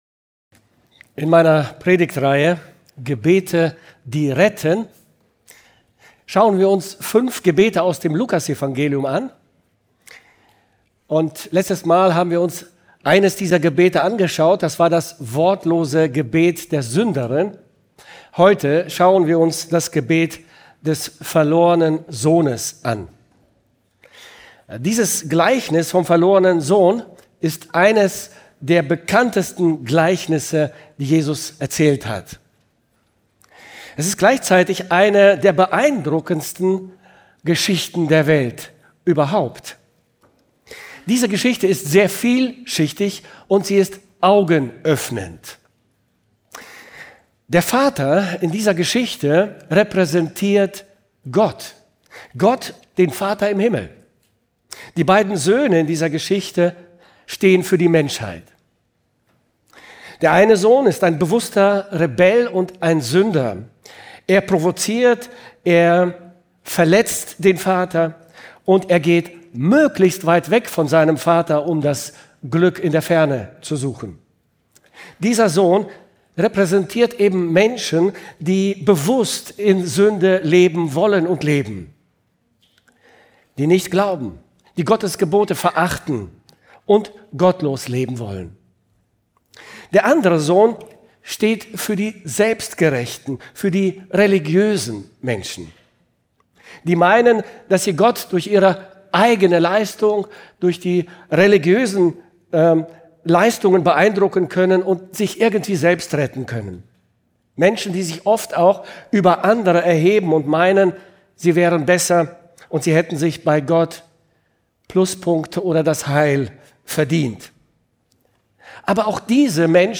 August 2025 Predigt-Reihe: Gebete die retten - 5 Gebete aus dem Lukasevangelium